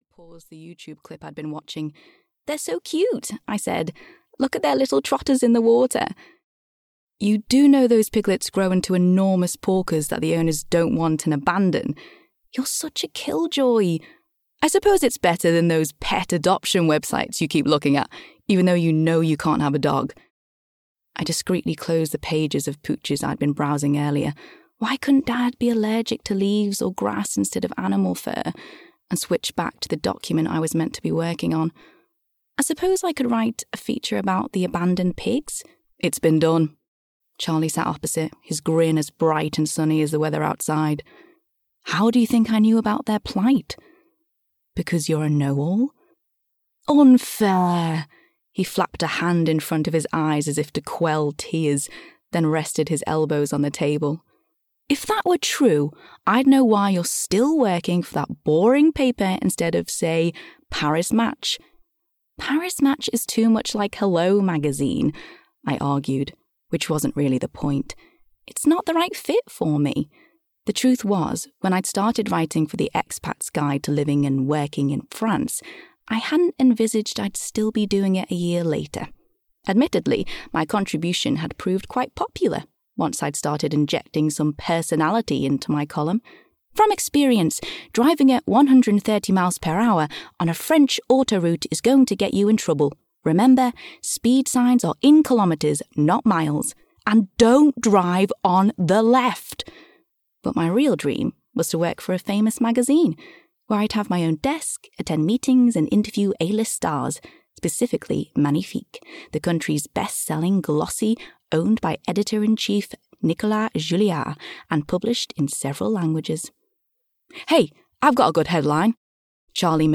Audiobook Escape to the Little French Cafe written by Karen Clarke.
Ukázka z knihy
escape-to-the-little-french-cafe-en-audiokniha